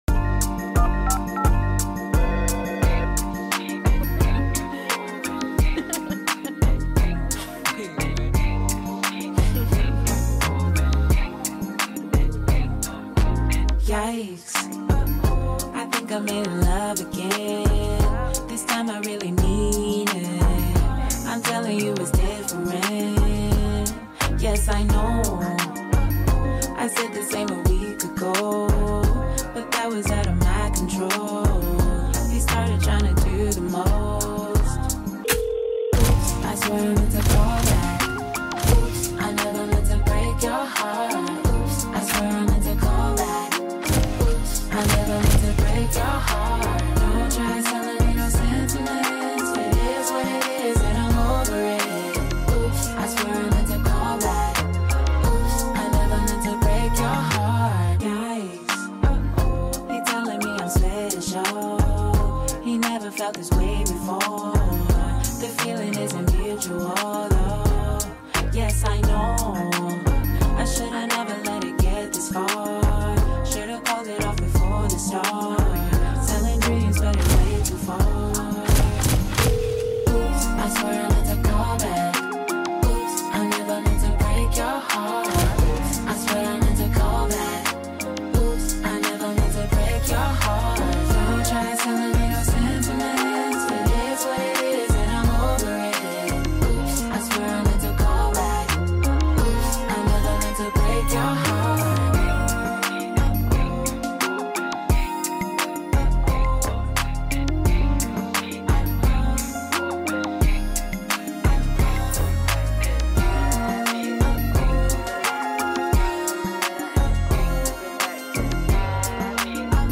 heartwarming song